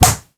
punch_slap_whack_hit_04.wav